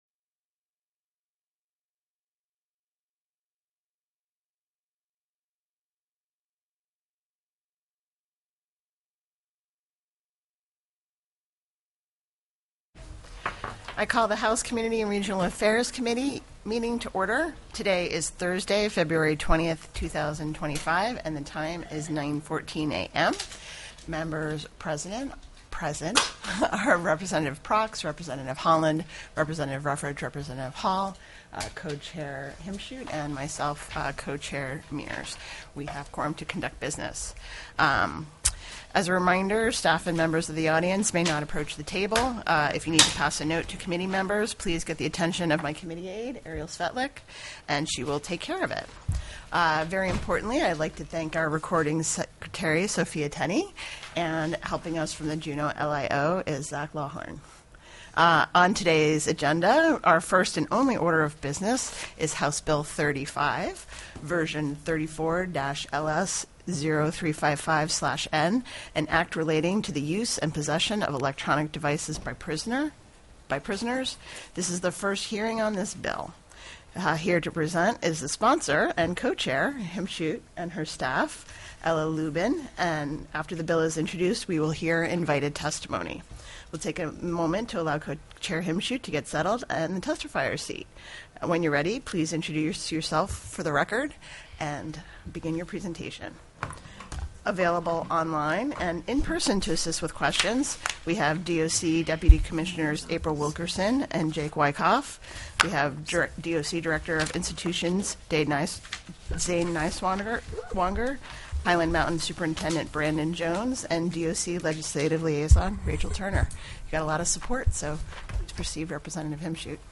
02/20/2025 09:00 AM House COMMUNITY & REGIONAL AFFAIRS
The audio recordings are captured by our records offices as the official record of the meeting and will have more accurate timestamps.